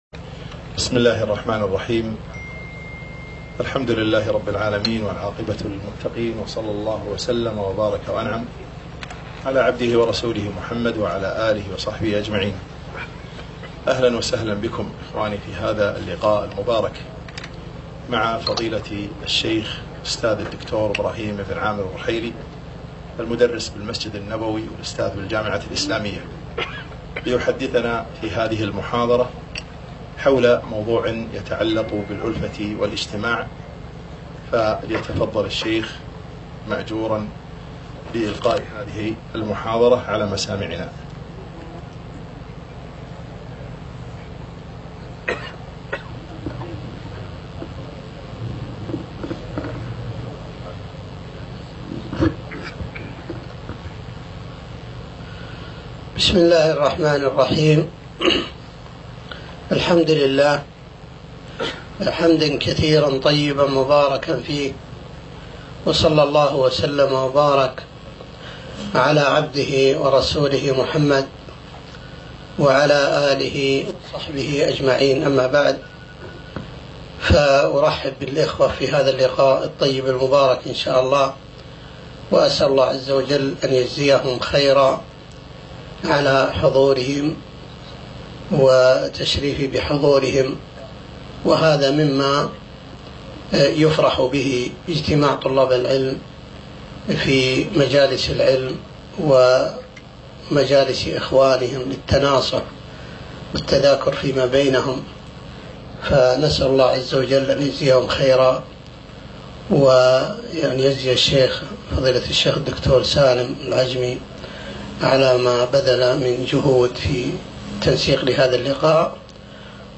مخيم شباب الدعوةالسلفية في الجهراء